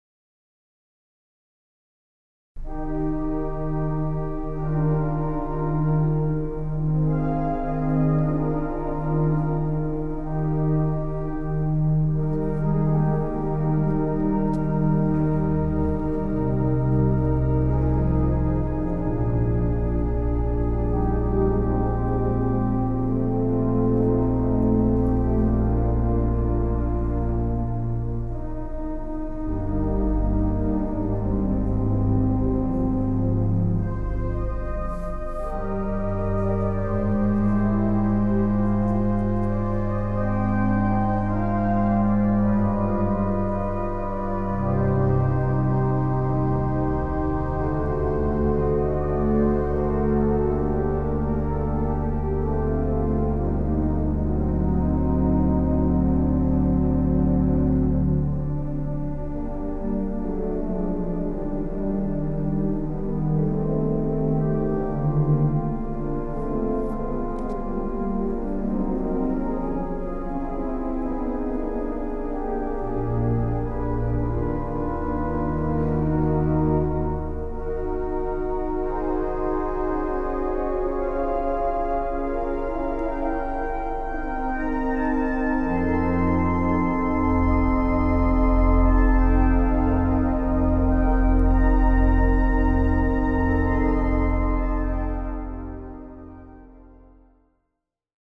Basilica del Sacro Cuore di Cristo Re
Concerto organistico in onore dei nuovi Santi Papa Giovanni XXIII e Papa Giovanni Paolo II
Preghiera, in si bemolle maggiore